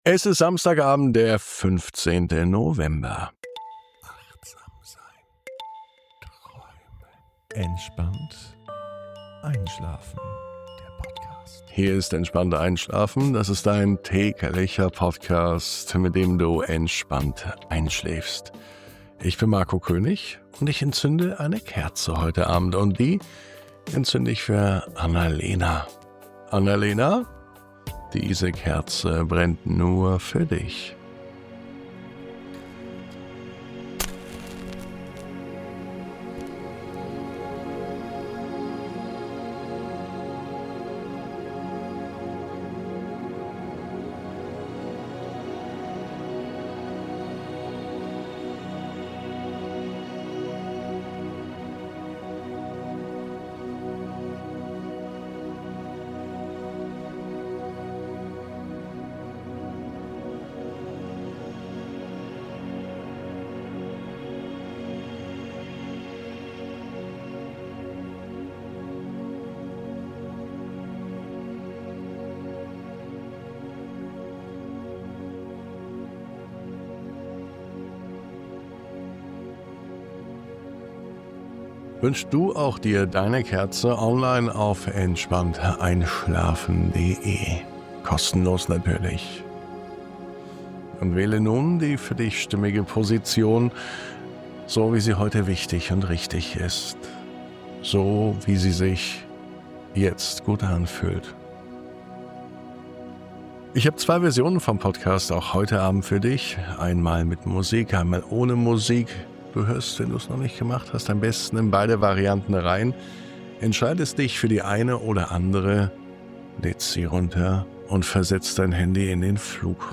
1115_MUSIK.mp3